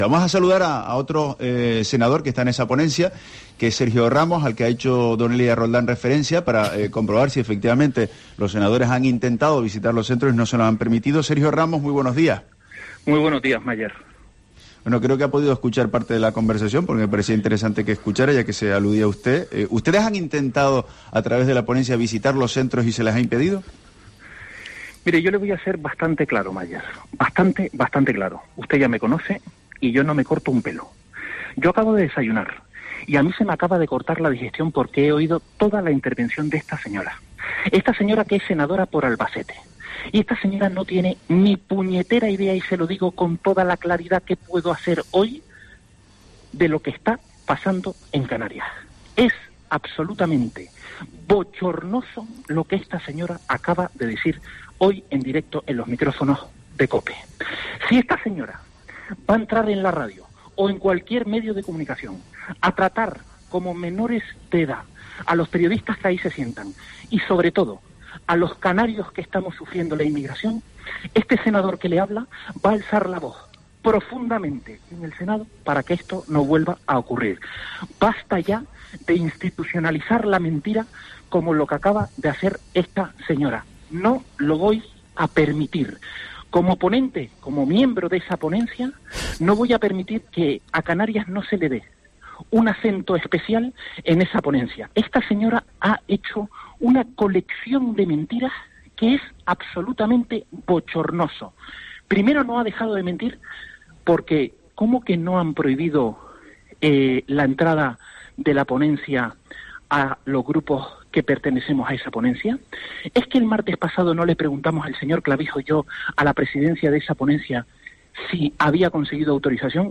AUDIO: Entrevista Sergio Ramos, senador del PP por Canarias